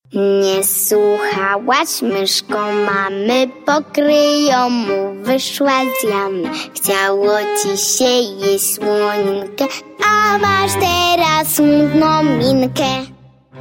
pełne rymów piosenki